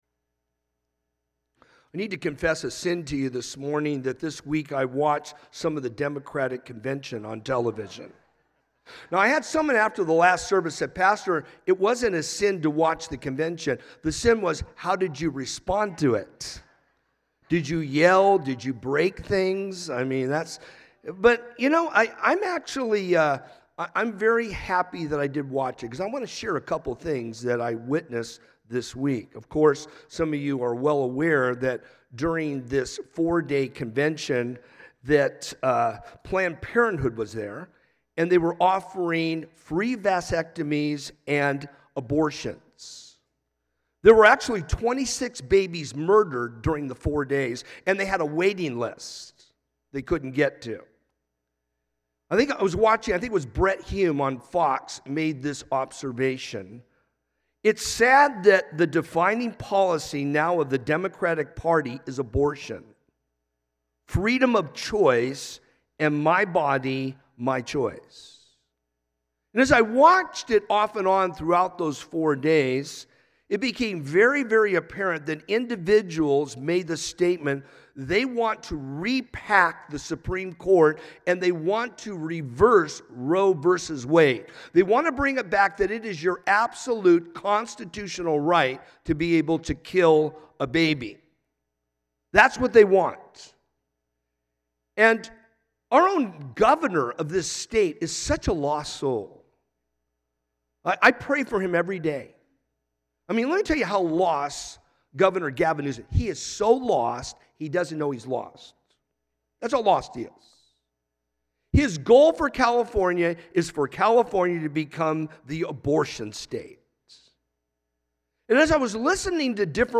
A message from the series "Topical."